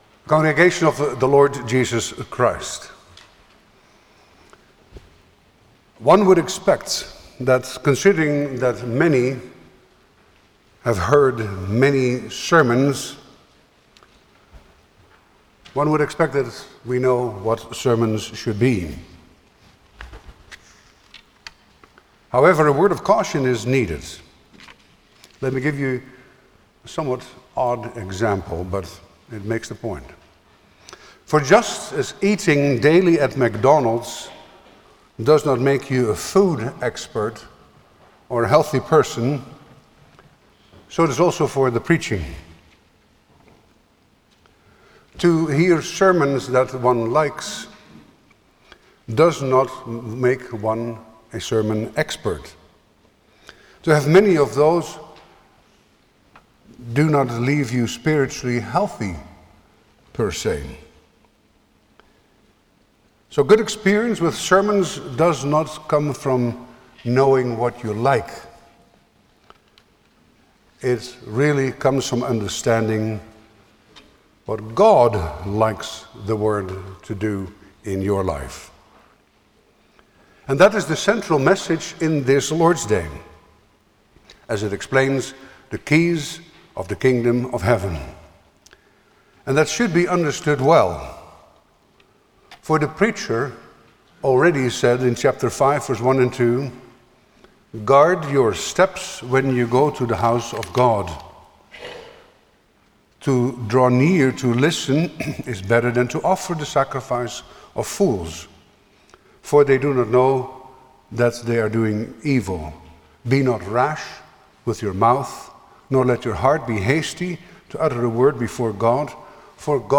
Service Type: Sunday afternoon
07-Sermon.mp3